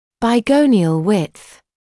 [baɪ’gəunɪəl wɪdθ][бай’гоуниэл уидс]ширина между гониальным углами нч